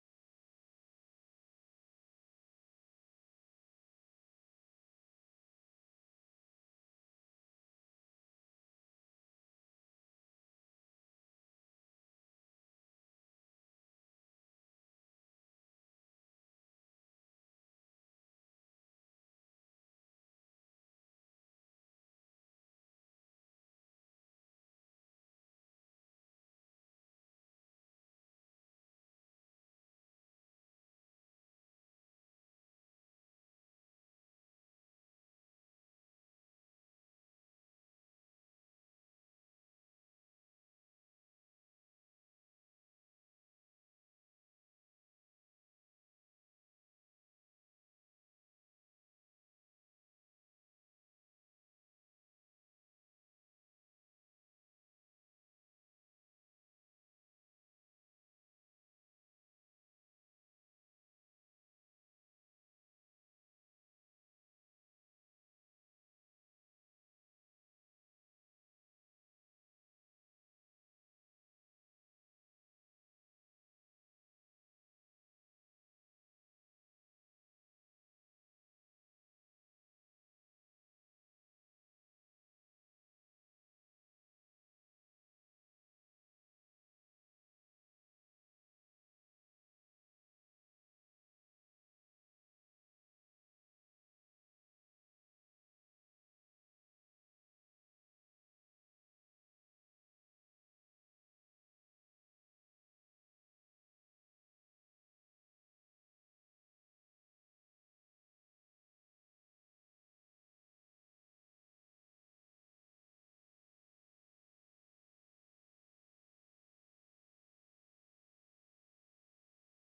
A message from the series "Seeking Jesus."